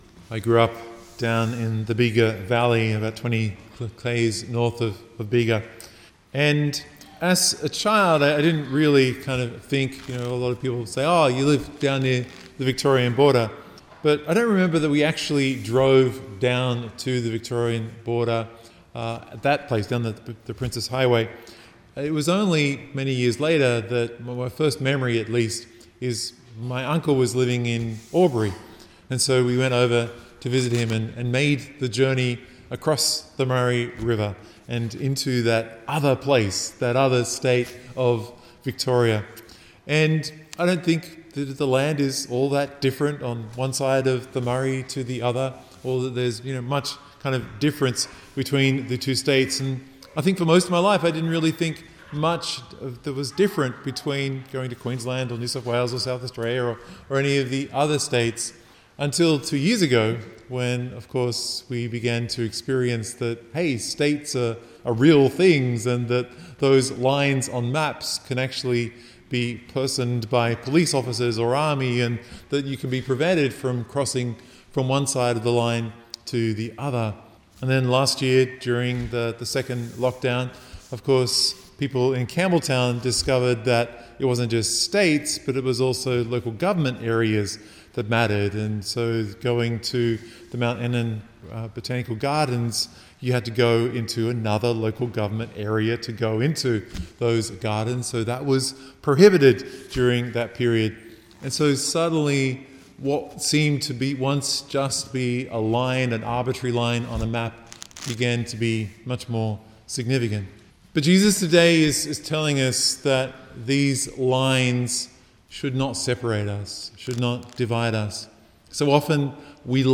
Download or Play MP3 MP3 media (Vigil)